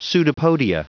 Prononciation du mot pseudopodia en anglais (fichier audio)